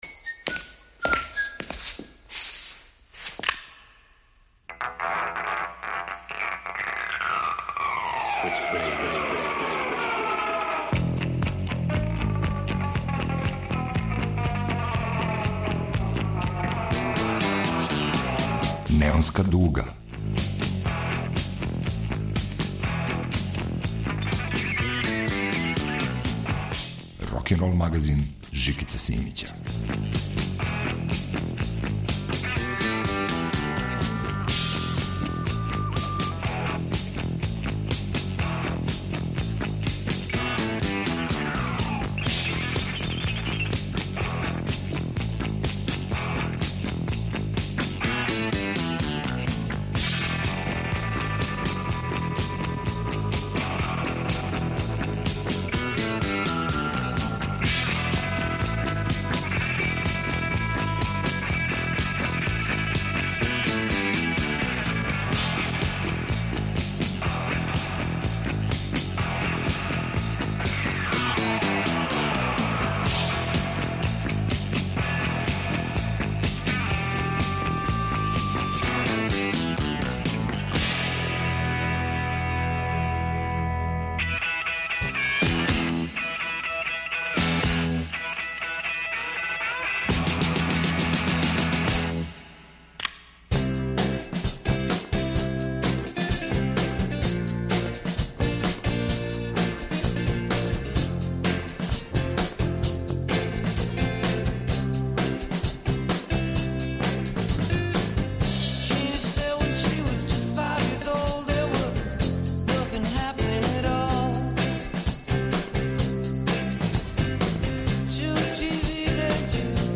Чист, дестилисан и рафинисан рокенрол у новој Неонској дуги.